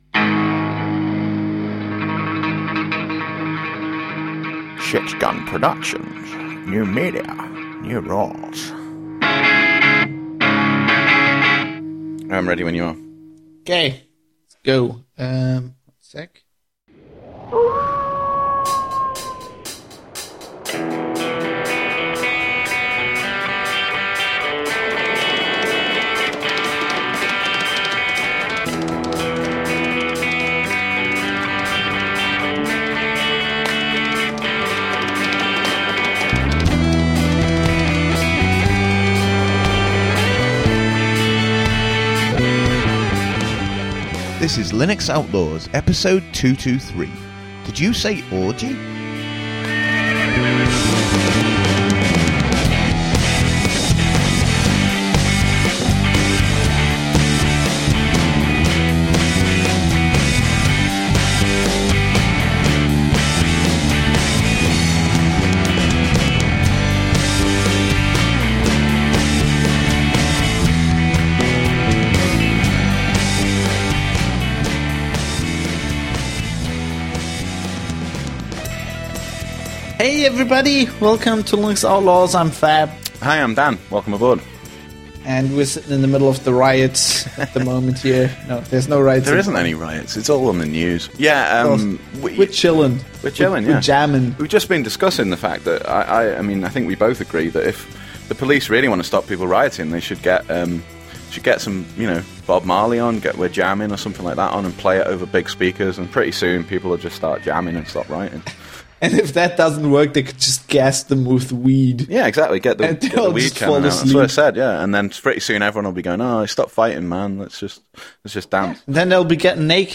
Now I’m back, I’ve been catching up on some of the press for Barefoot Into Cyberspace, and I’d like to draw your attention to two longish interviews that aired last week and that I think are worth some of your time, as well as another one that’s happening this week.